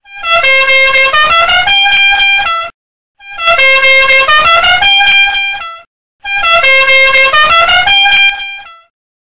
Alarmton – Laut Klingelton Kostenlos
Kategorien Alarm